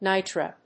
音節ni・tre 発音記号・読み方
/nάɪṭɚ(米国英語), nάɪtə(英国英語)/